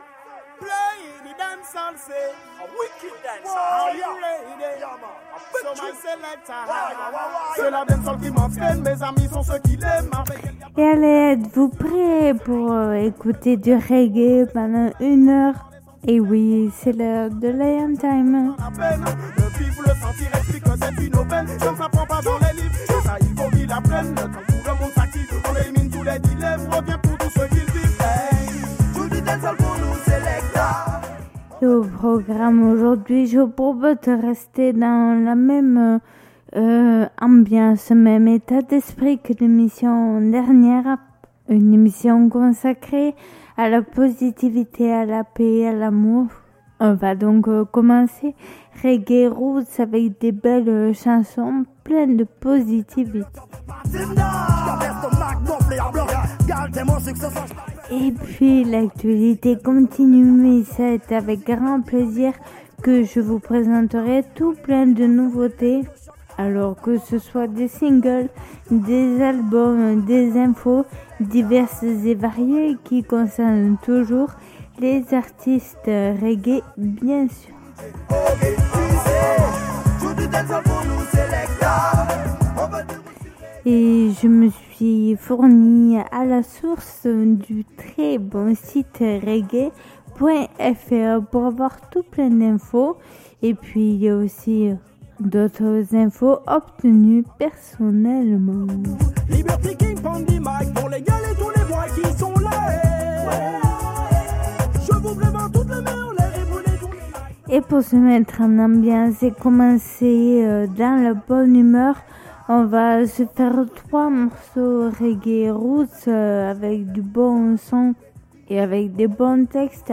Émission qui invite au voyage et à la découverte du roots du reggae et du dancehall.